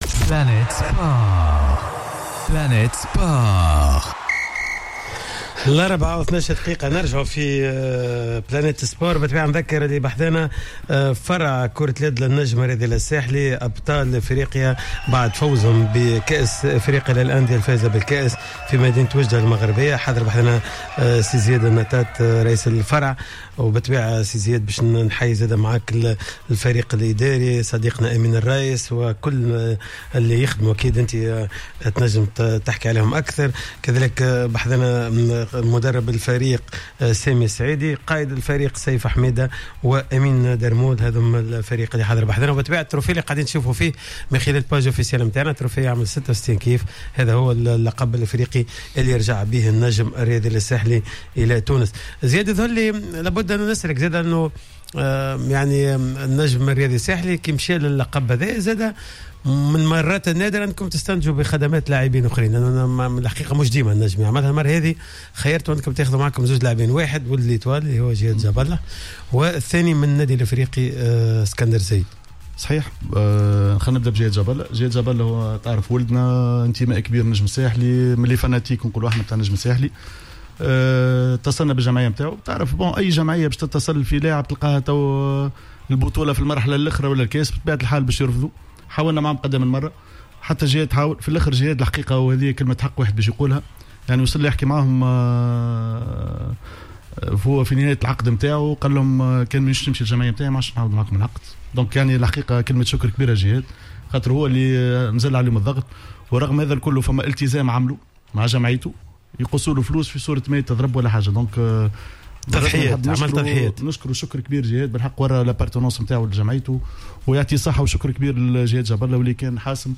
إستضافت حصة "Planète Sport" اليوم الثلاثاء 16 أفريل 2019 فريق النجم الساحلي بعد تتويجه مؤخرا بلقب بطولة إفريقيا للأندية الفائزة بالكأس لكرة اليد.